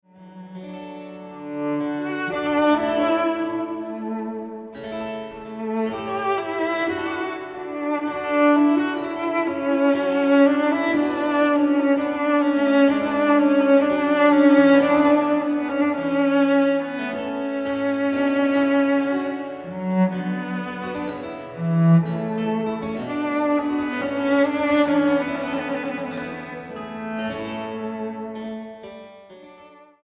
violoncelo